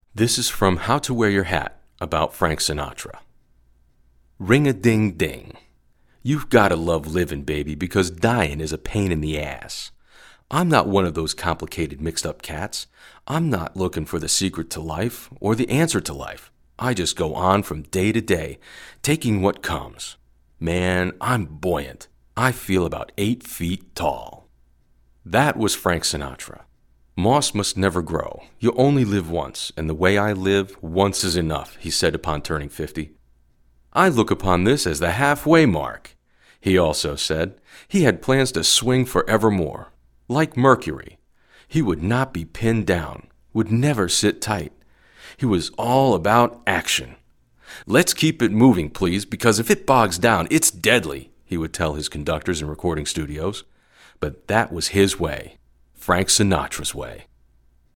Warm, friendly, guy next door, conversational, commanding, character,
Sprechprobe: Sonstiges (Muttersprache):